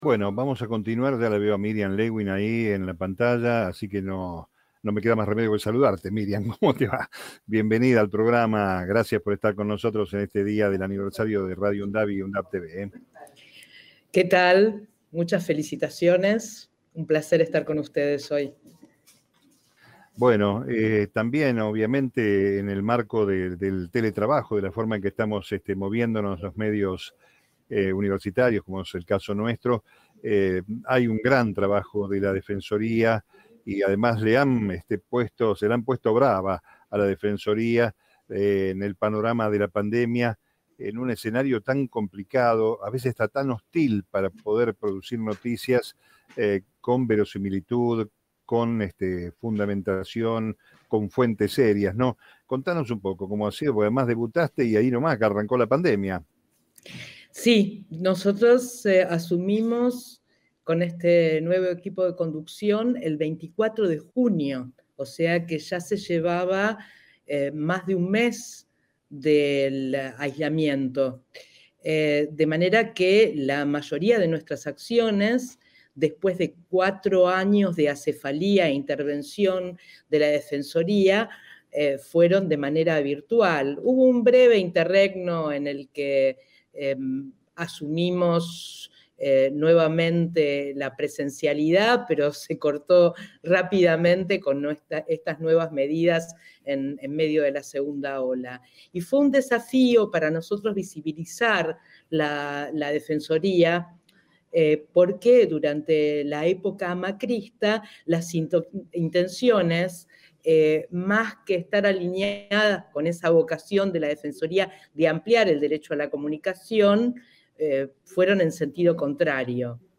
Entrevista a Miriam Lewin en Meridiano Virtual | Radio UNDAV